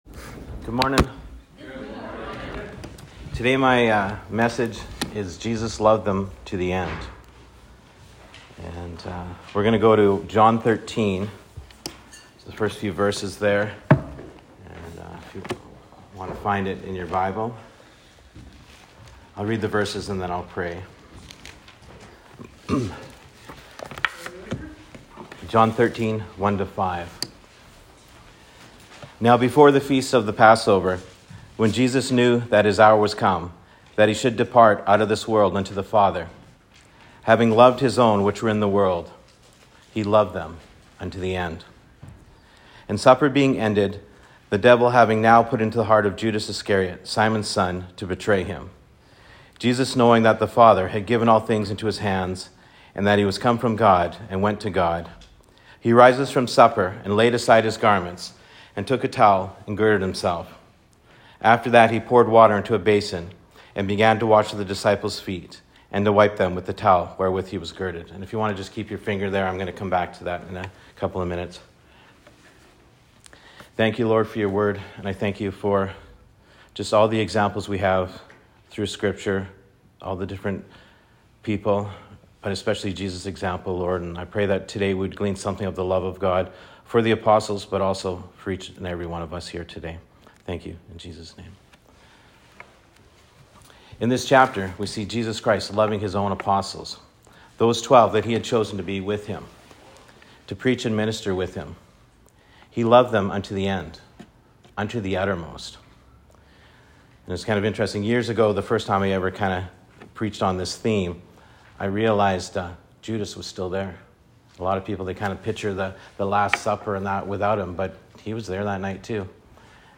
The Twelve Apostles #4 - Jesus Loved Them To The End These were my introductory comments when I originally wrote out this message: I preach once a week at the Gospel Mission.